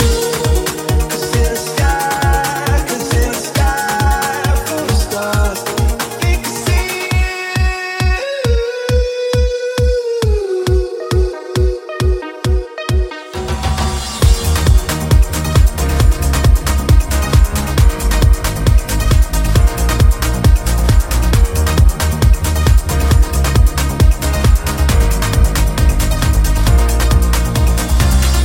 Genere: afrobeat,afrohouse,deep,remix,hit